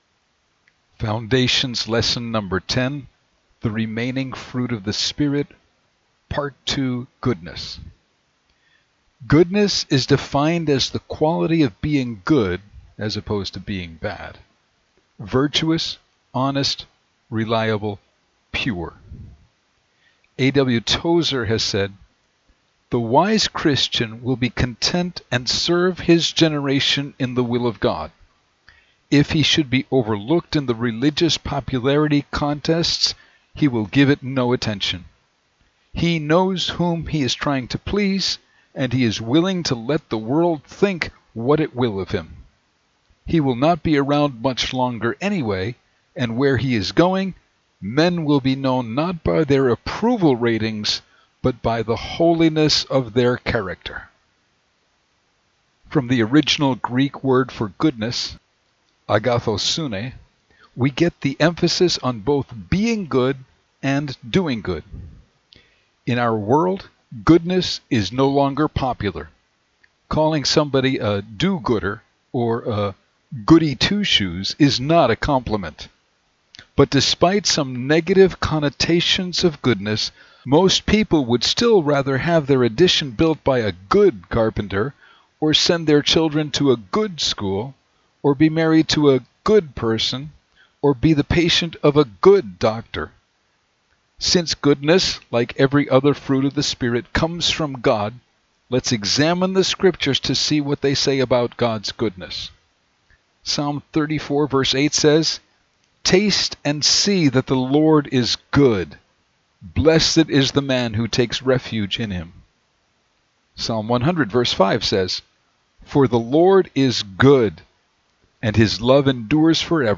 Spoken Lesson, Part Two: Goodness